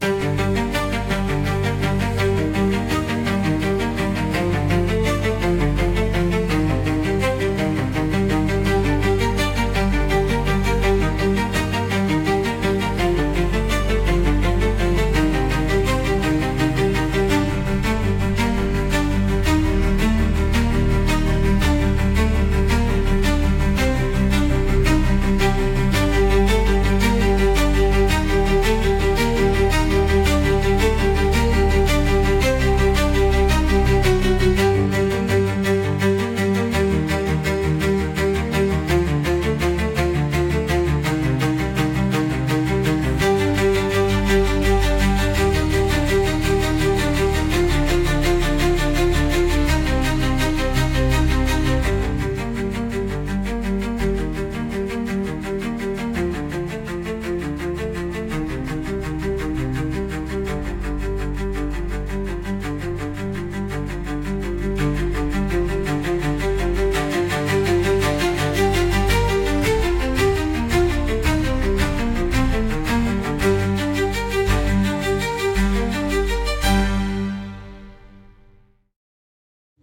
Instrumental- Thread Between Realms - 1.19 sec